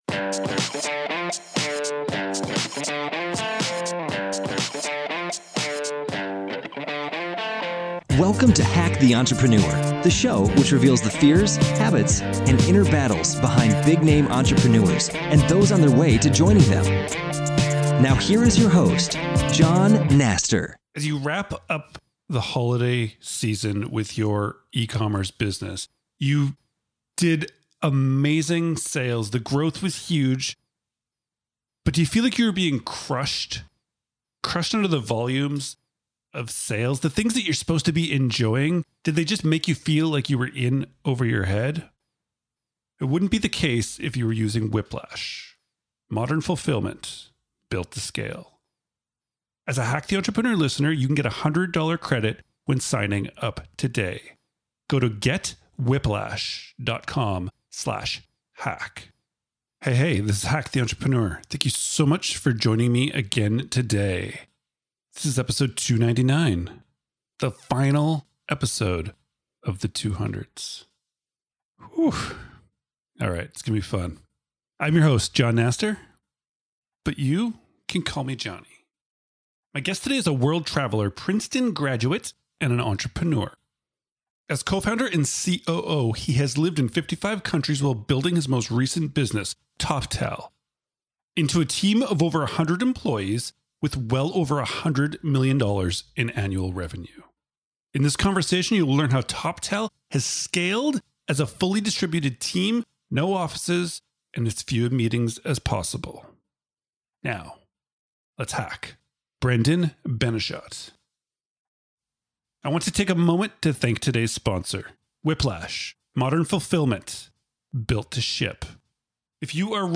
In this conversation, you will learn how Toptal has scaled as a fully-distributed team — no offices and as few meetings as possible.